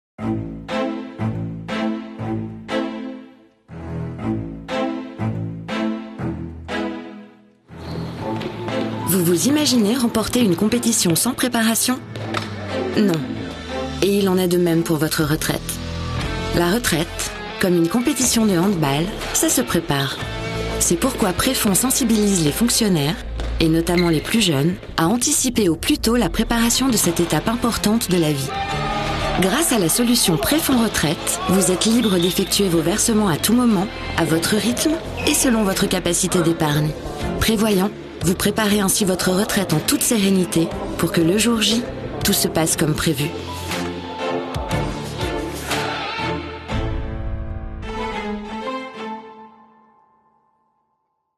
Vidéos d'entreprise
Artiste inspirée, attentive, disponible et polyvalente, avec une voix grave-médium.
Cabine DEMVOX, micro Neumann TLM 103, Scarlett 4i4 et ProTools Studio sur un Mac M1